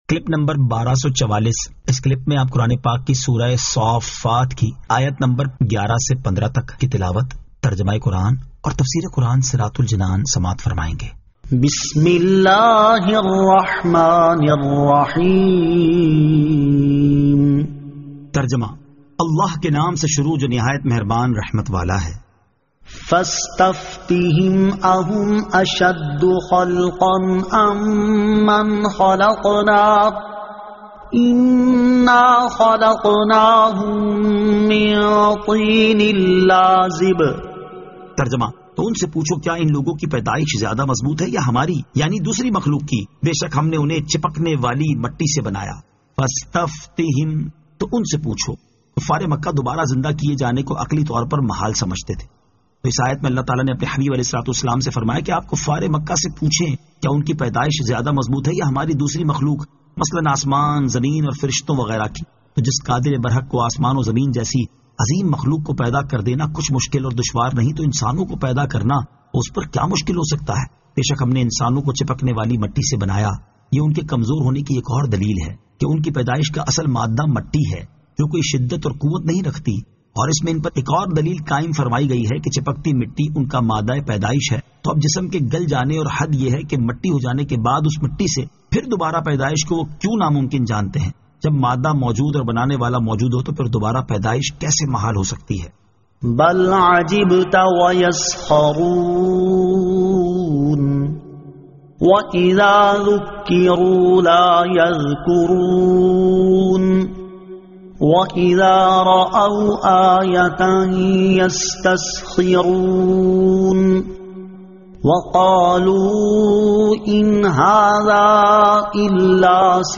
Surah As-Saaffat 11 To 15 Tilawat , Tarjama , Tafseer